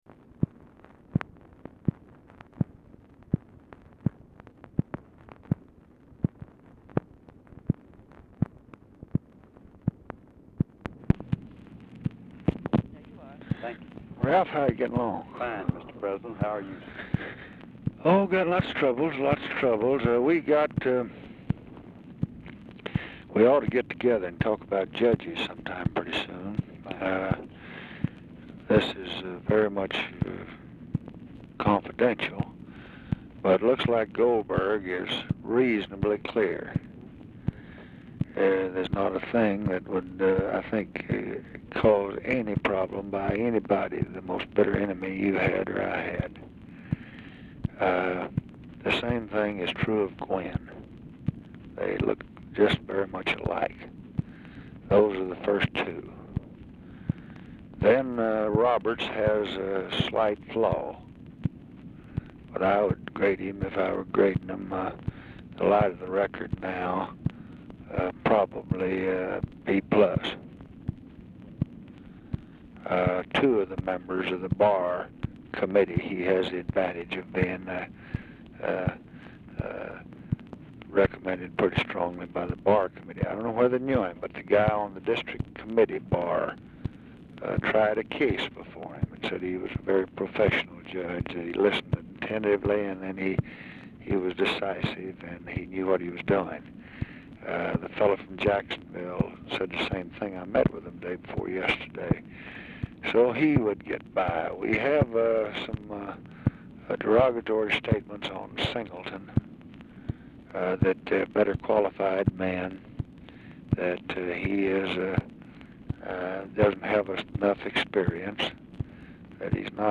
Telephone conversation # 10131, sound recording, LBJ and RALPH YARBOROUGH, 5/20/1966, 5:30PM | Discover LBJ
Format Dictation belt
Location Of Speaker 1 Oval Office or unknown location
Specific Item Type Telephone conversation